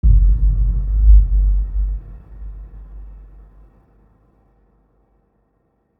Low End 07.wav